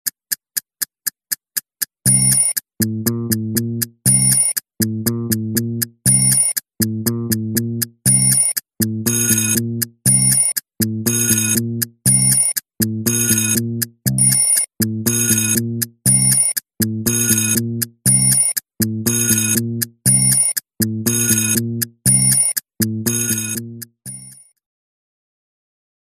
tick-tock-clock_14100.mp3